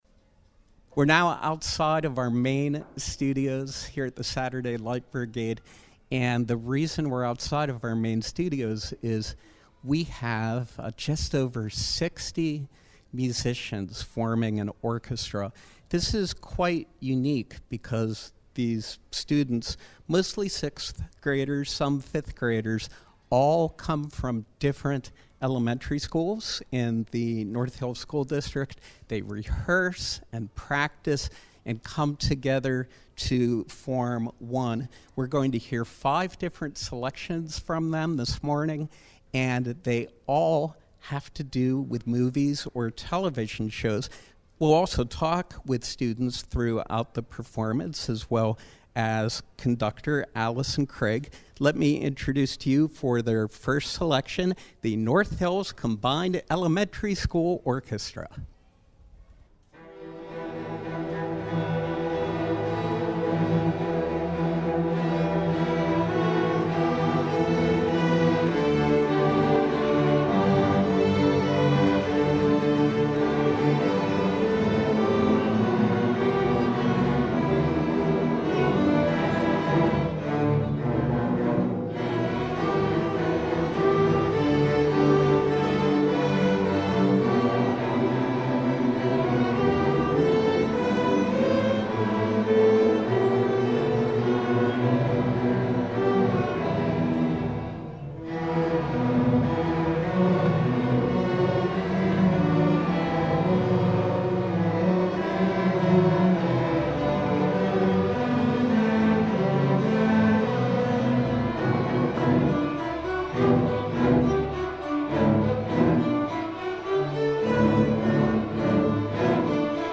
From 03/21/2020: Students from Highcliff, McIntyre, Ross, and West View elementary schools perform songs from film and television.